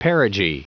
Prononciation du mot perigee en anglais (fichier audio)
Prononciation du mot : perigee